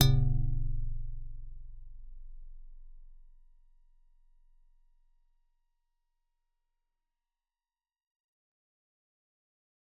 G_Musicbox-F0-f.wav